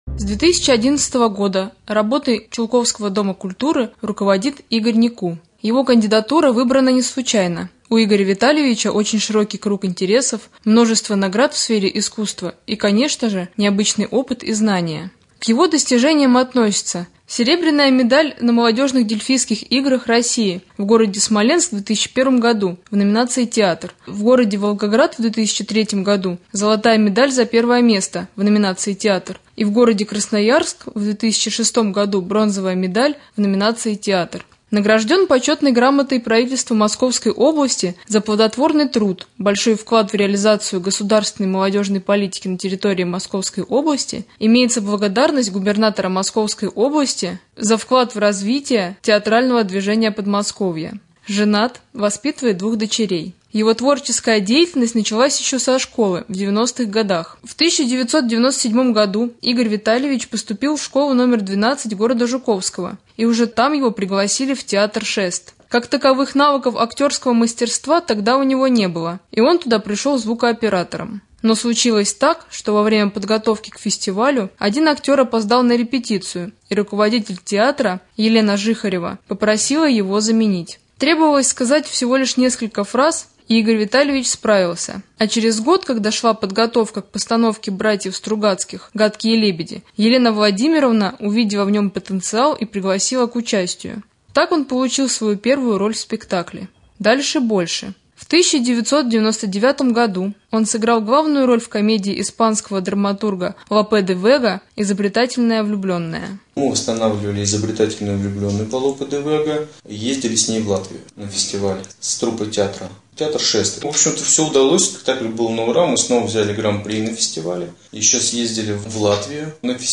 Рубрика «Актуальное интервью».